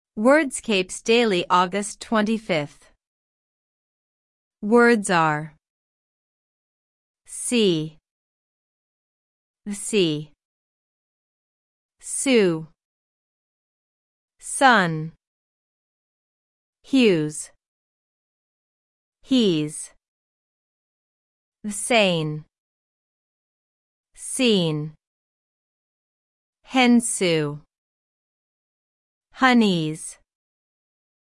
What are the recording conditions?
On this page you’ll get the full Wordscapes Daily Puzzle for August 25 Answers placed in the crossword, all bonus words you can collect along the way, and an audio walkthrough that can read the answers to you at the speed you like while you’re still playing.